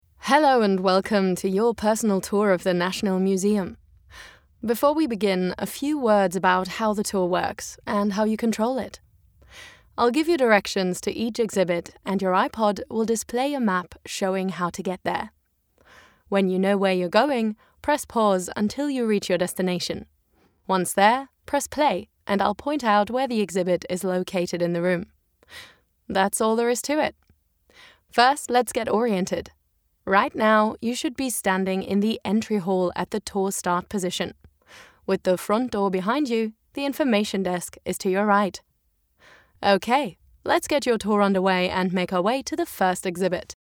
Female
Bright, Character, Cheeky, Children, Confident, Cool, Friendly, Natural, Soft, Versatile, Young, Engaging, Warm
Her voice is relatable, contemporary and youthful with a warm and textured sound.
Microphone: Sennheiser MKH 416, Rode NT1-A